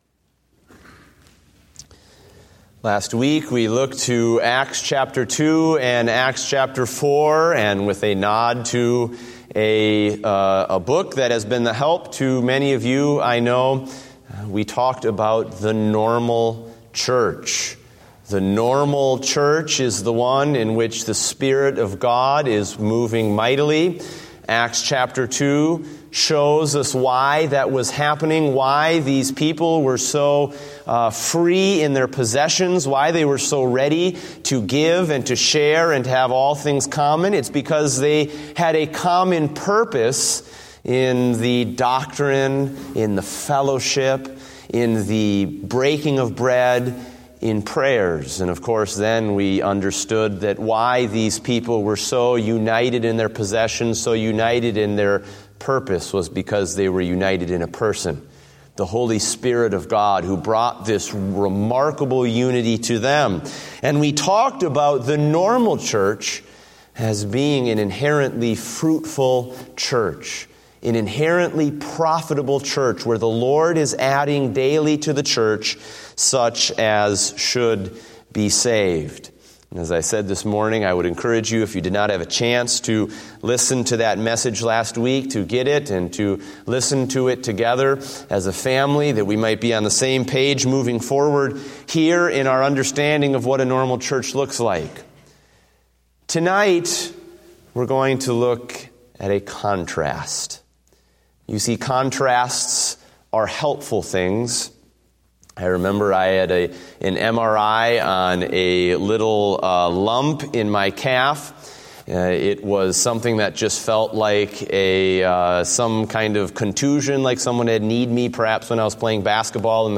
Date: January 3, 2016 (Evening Service)